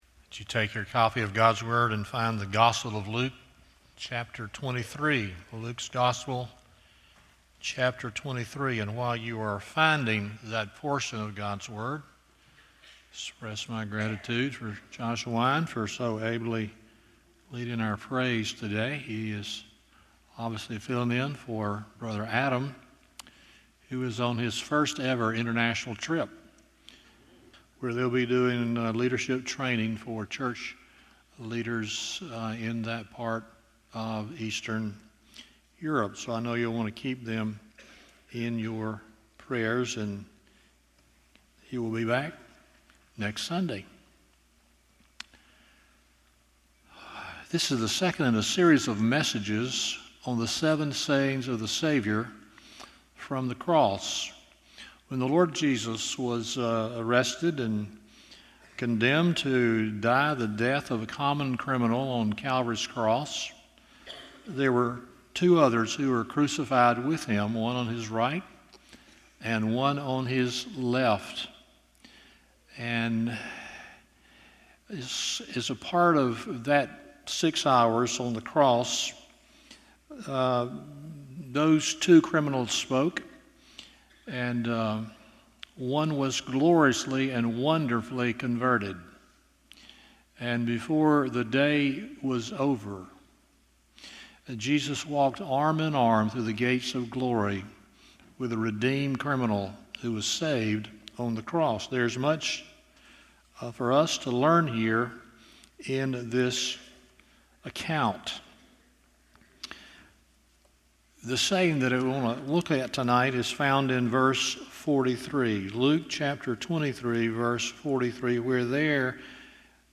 Luke 23:43 Service Type: Sunday Evening 1.